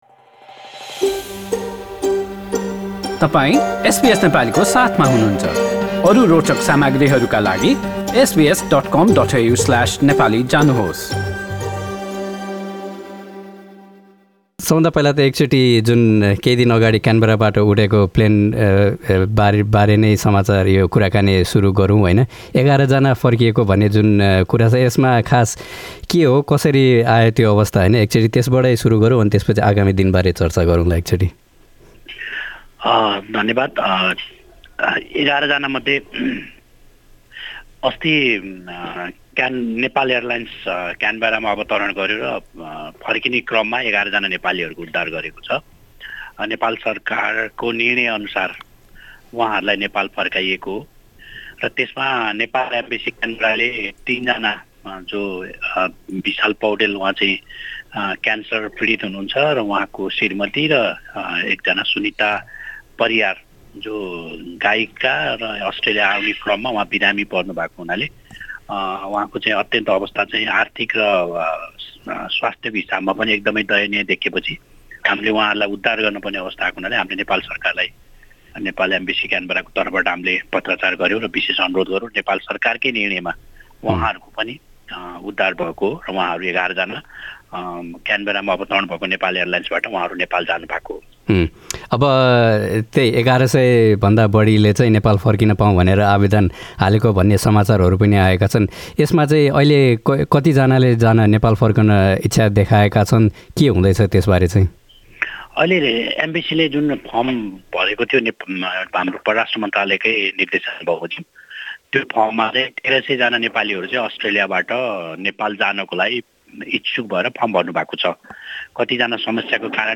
अस्ट्रेलियामा रहेका एक हजार भन्दा बढी नेपालीहरूले कोरोनाभाइरस अन्तर्गतका समस्याका कारण नेपाल फर्कन आवेदन हालेता पनि "अत्यावश्यक" रहेकाहरूलाई प्राथमिकतामा राखिने राजदूत महेशराज दाहालले बताएका छन्। हालै एघार यात्रु सहित क्यानबेराबाट नेपाल एयरलाइन्स फर्किएको लगायतका विषयबारे एसबीएस नेपालीसँग अस्ट्रेलियाका लागि नेपाली राजदूतको कुराकानी यहाँ सुन्नुहोस्।